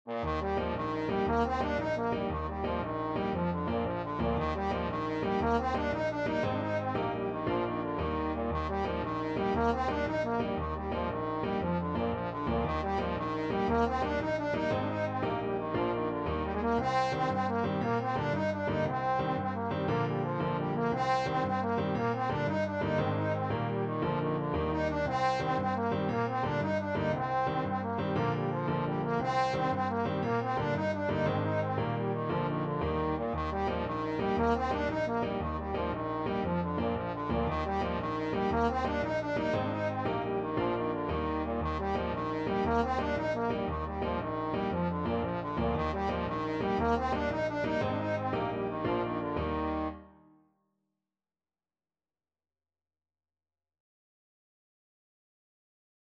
Trombone
6/8 (View more 6/8 Music)
Bb3-Eb5
Eb major (Sounding Pitch) (View more Eb major Music for Trombone )
With energy .=c.116
Irish